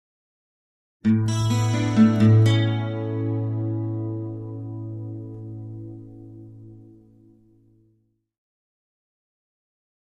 Acoustic Guitar - Music Stinger - Picked With Chorus 1